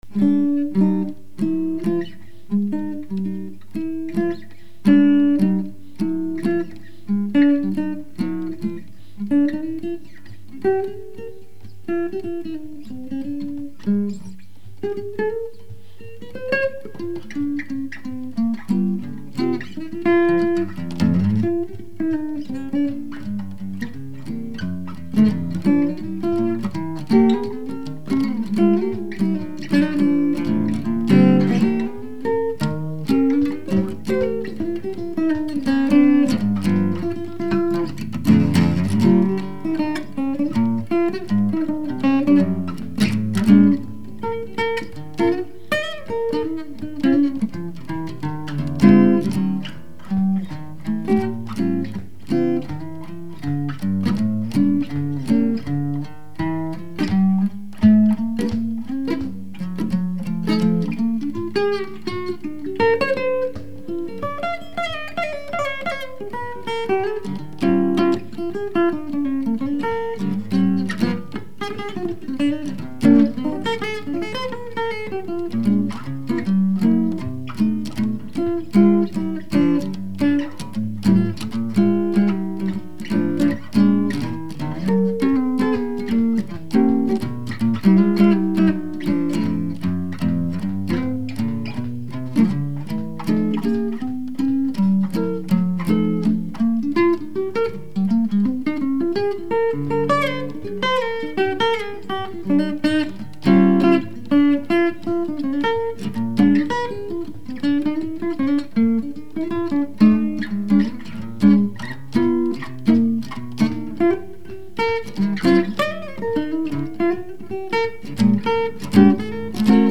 Chitarra solo